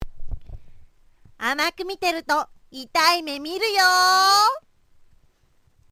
性別：女
口調：「〜だね」・「〜だよ」など、元気な口調